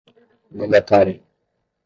Uitgesproken als (IPA) /mɐ̃.daˈta.ɾi.u/